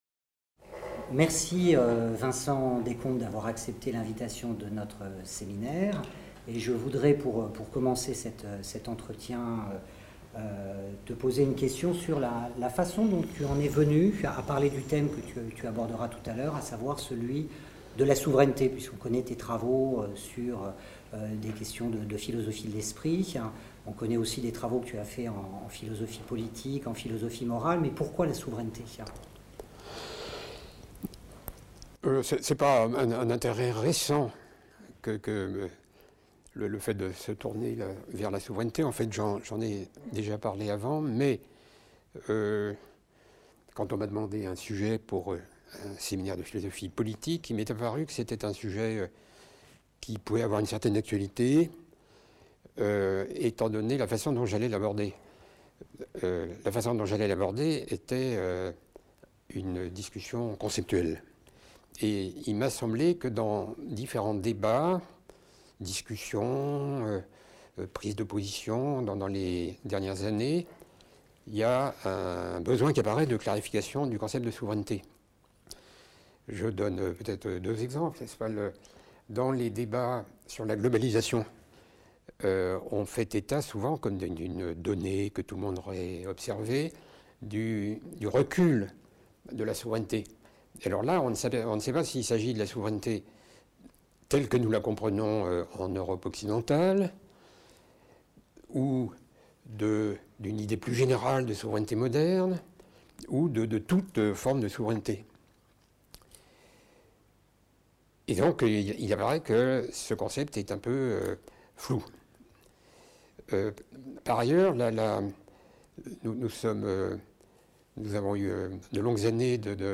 Autour de la souveraineté : Entretien avec Vincent Descombes | Canal U